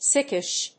音節sick・ish 発音記号・読み方
/síkɪʃ(米国英語)/